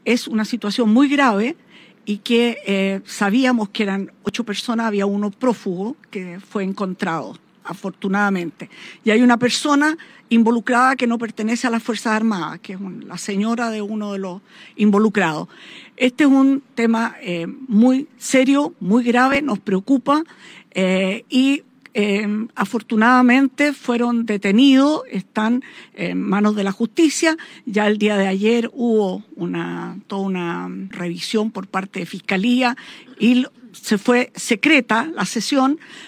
En un punto de prensa, la secretaria de Estado confirmó que sostuvo una reunión con los comandantes en jefe de las Fuerzas Armadas, subsecretarios del ramo y el jefe del Estado Mayor Conjunto para abordar tanto la seguridad de los recintos militares como el caso específico.